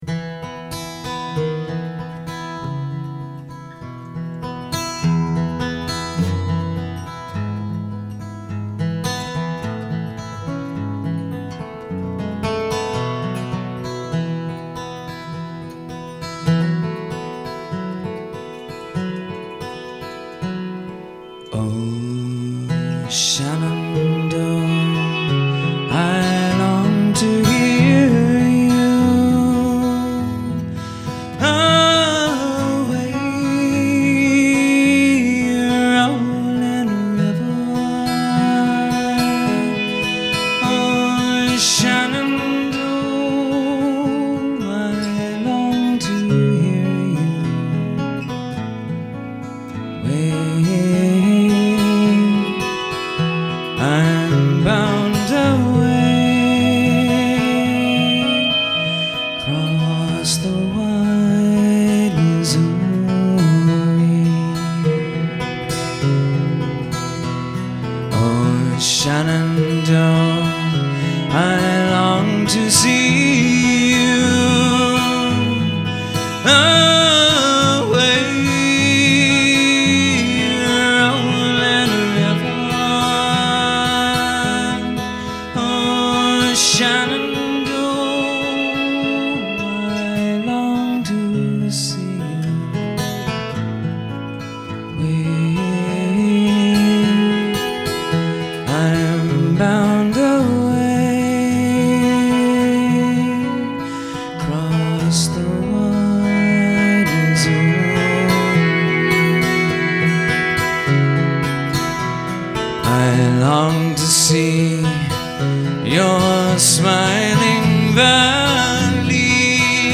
A moment in time at Club Passim – 1998
recorded at Club Passim in 1998.
No matter what it was used for, it’s just a wonderful melody.